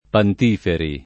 [ pant & feri ]